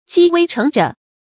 积微成着 jī wēi chéng zhù
积微成着发音